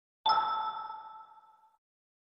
Pickup01.wav